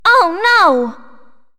One of Princess Daisy's voice clips in Mario Party 6